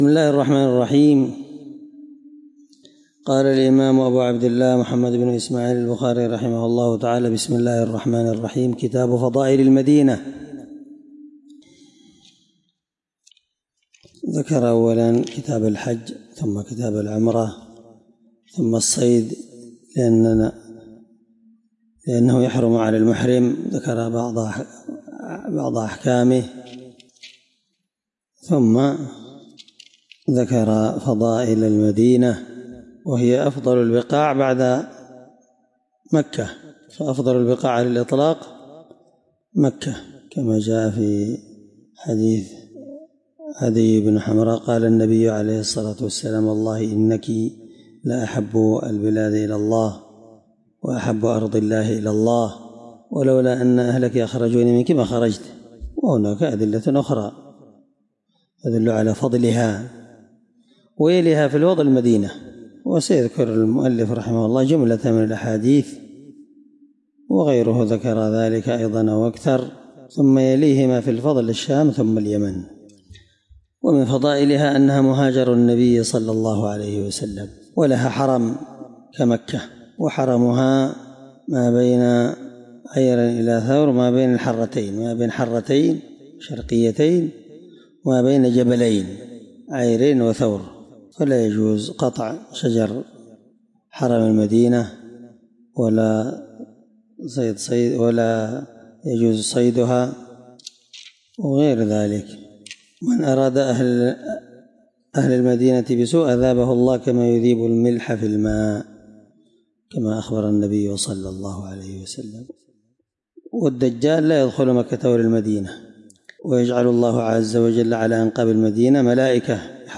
الدرس 1من شرح كتاب فضائل المدينة حديث رقم(1867-1868 )من صحيح البخاري